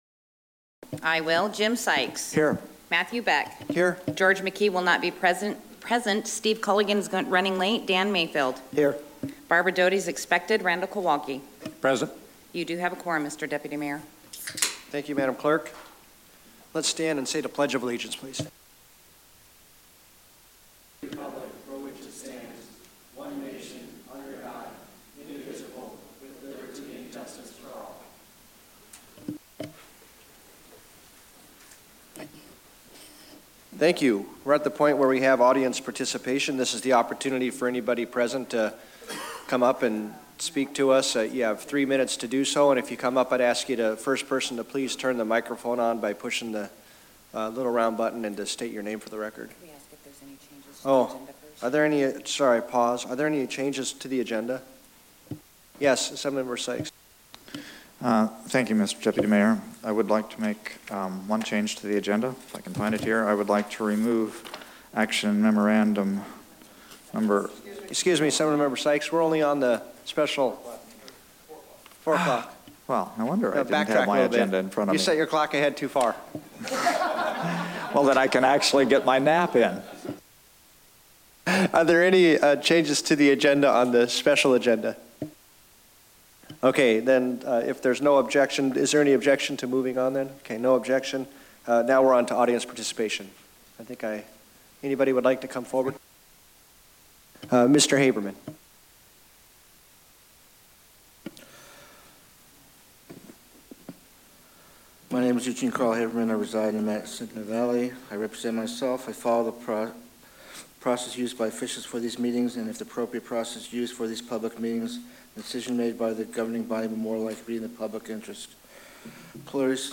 MatSu Borough Special Meeting 3.15.2016
Mar 16, 2016 | Borough Assembly Meetings